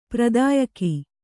♪ pradāyaki